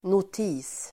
Uttal: [not'i:s]